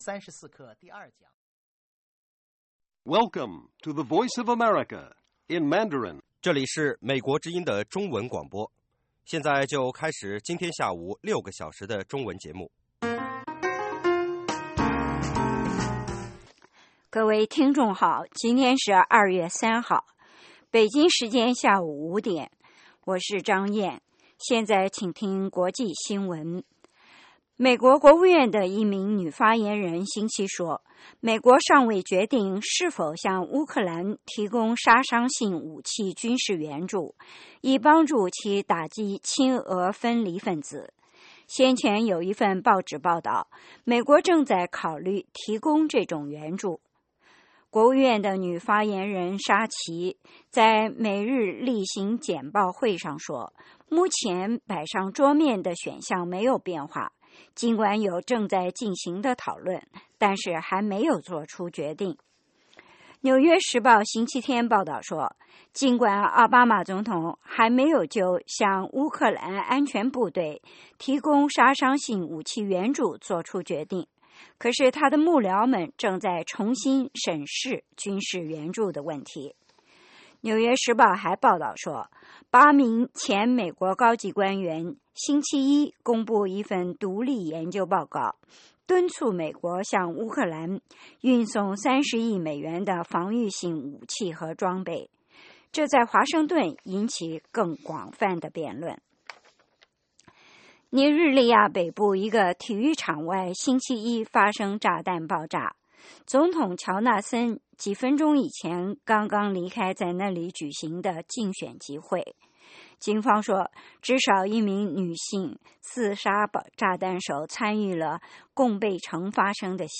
北京时间下午5-6点广播节目。 内容包括国际新闻和美语训练班（学个词，美国习惯用语，美语怎么说，英语三级跳，礼节美语以及体育美语）